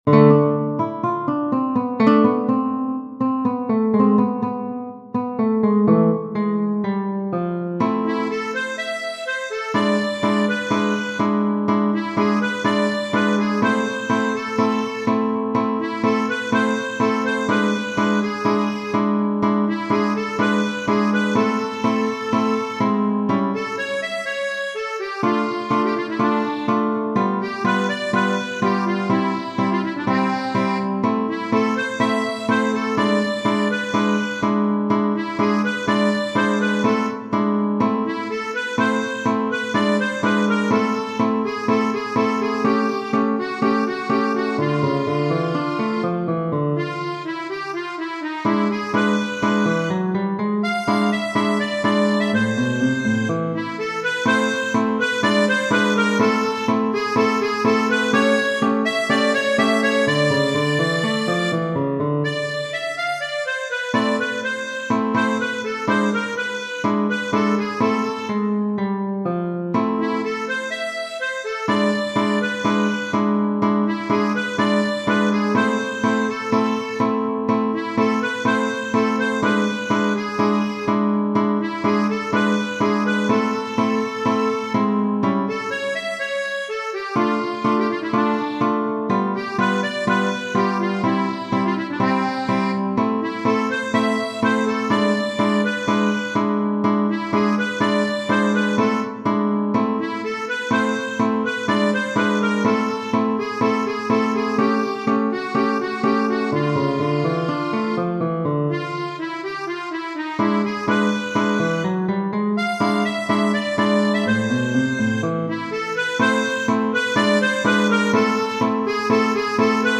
Genere: Ballabili
Duelo_criollo_(Tango)_0.mp3